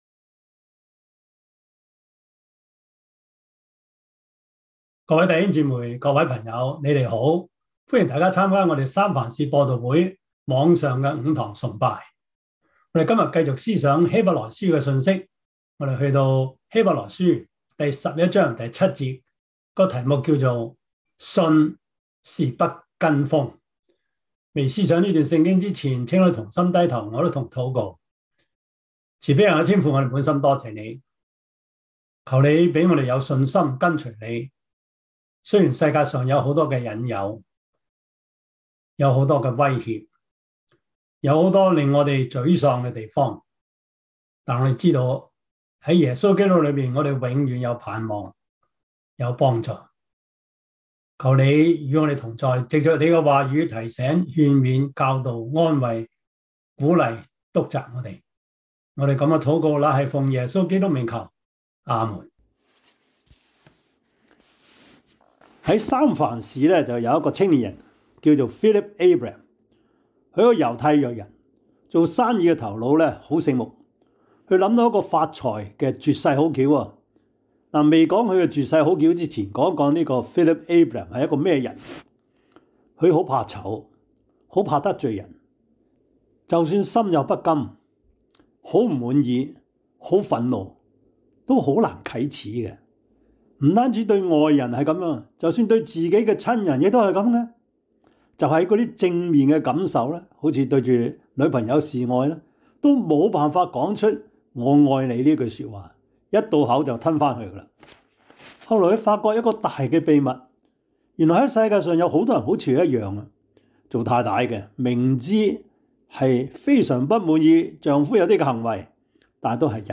希伯來書 11:7 Service Type: 主日崇拜 希 伯 來 書 11:7 Chinese Union Version
Topics: 主日證道 « 我在這裡, 請差遣我!